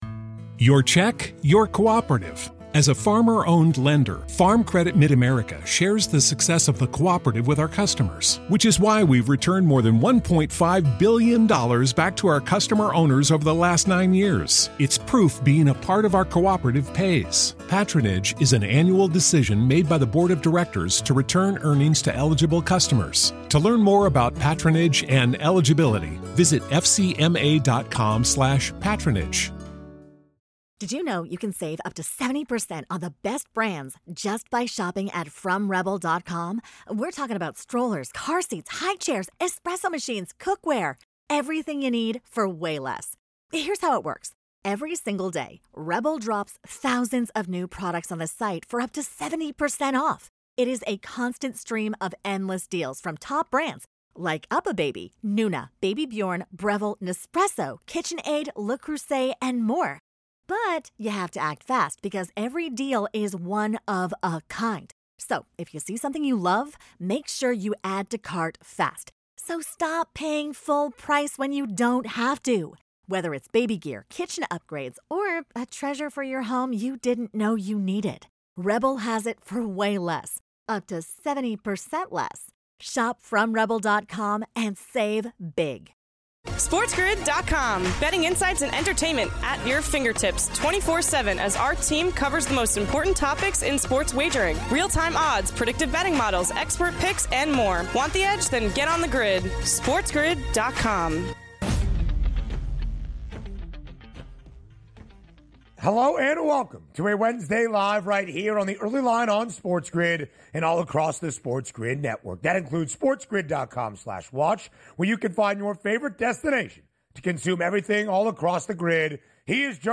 is then joined on Radio Row by special guest Ed McCaffrey to talk his story and lengthy career in the NFL!